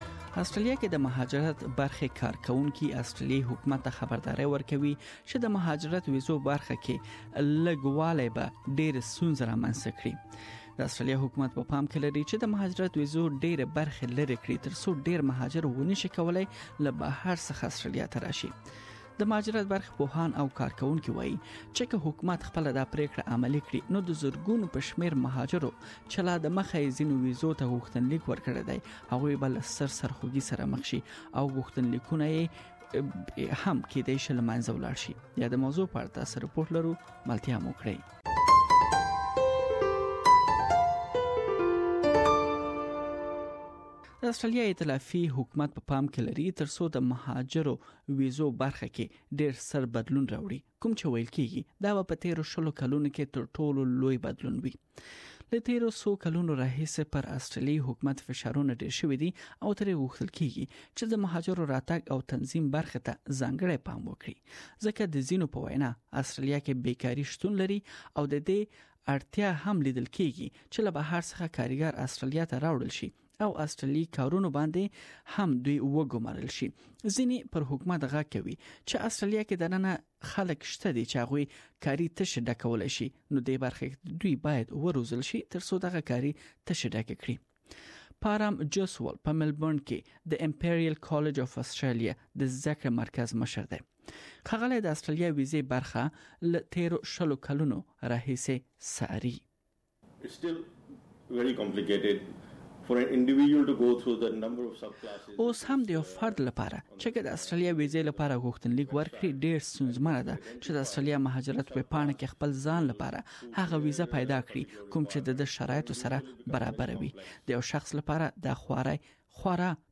Please listen to the full report in Pashto.